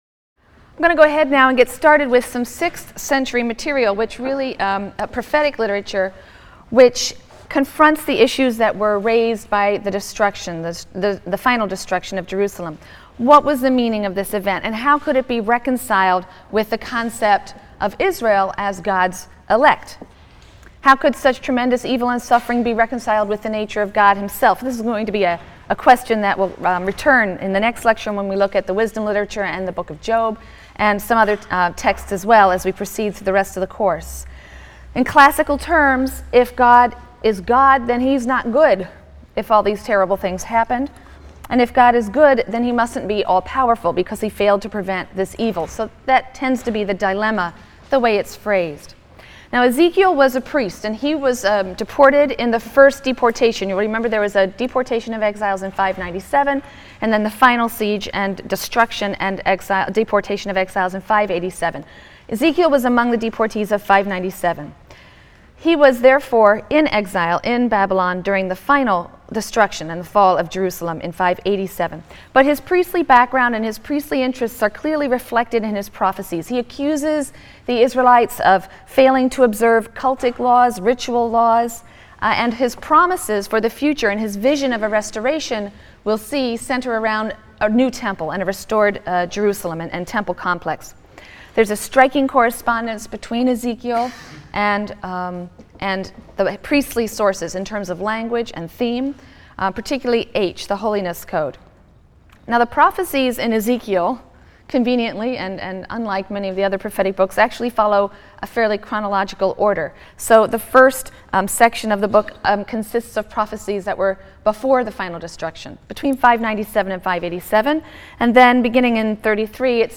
RLST 145 - Lecture 19 - Literary Prophecy: Perspectives on the Exile (Jeremiah, Ezekiel and 2nd Isaiah) | Open Yale Courses